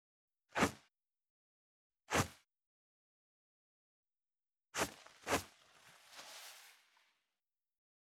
172.ティッシュ【無料効果音】